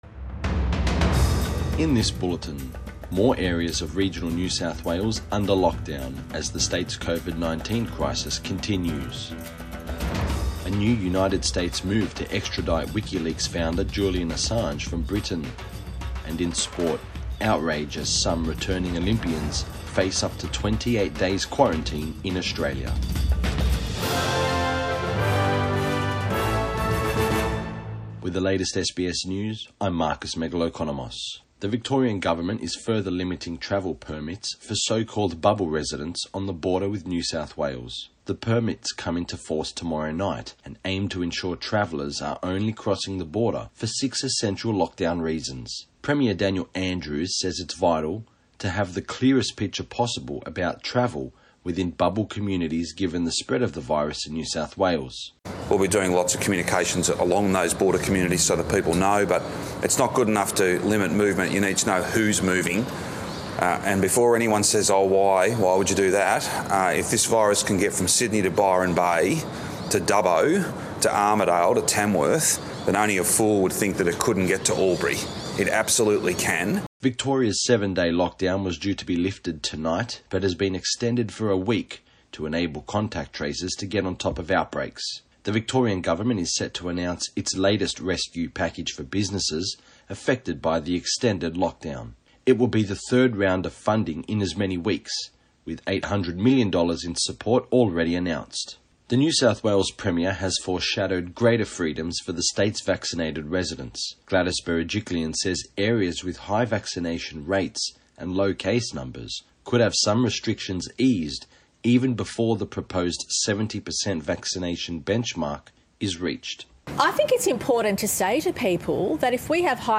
AM Bulletin 12 August 2021